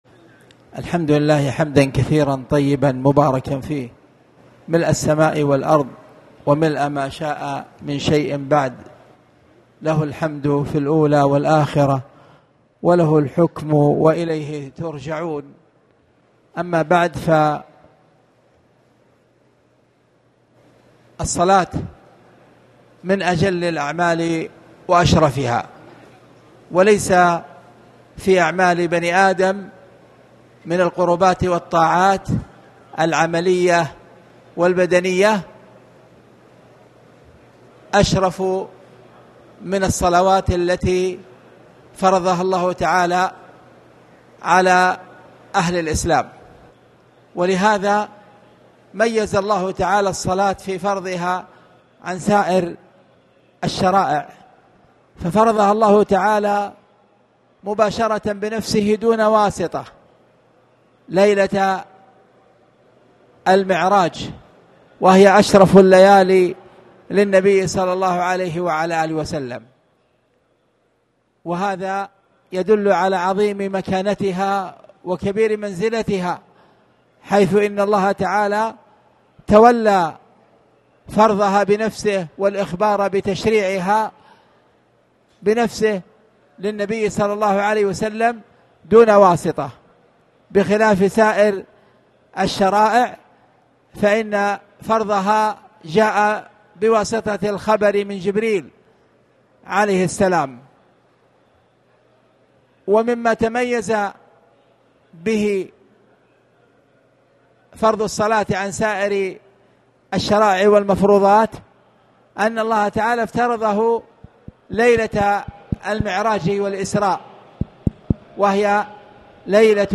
تاريخ النشر ١٦ محرم ١٤٣٩ هـ المكان: المسجد الحرام الشيخ